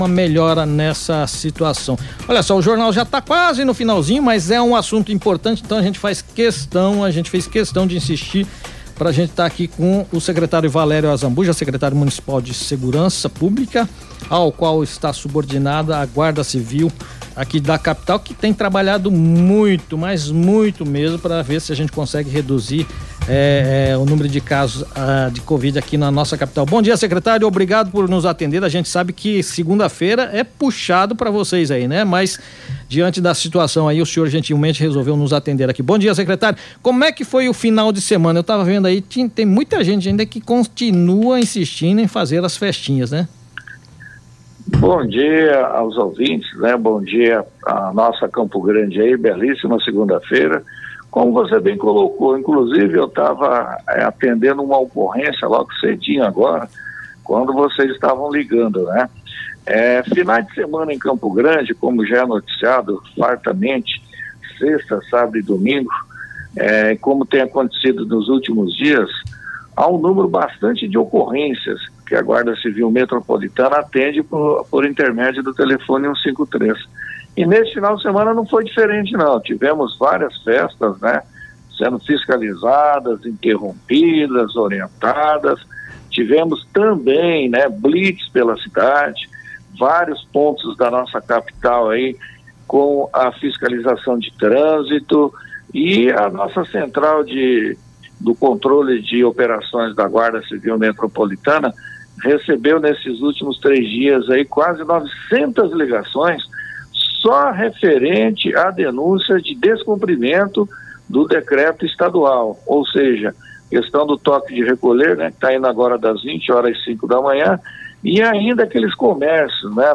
O secretário de Segurança Pública, Valério Azambuja durante entrevista ao programa MS no Rádio, da 194,7 FM, comentou sobre o trabalho da Guarda Civil Metropolitana, que é um dos órgãos responsáveis pelo controle do Toque de Tecolher, seguindo o Decreto Estadual.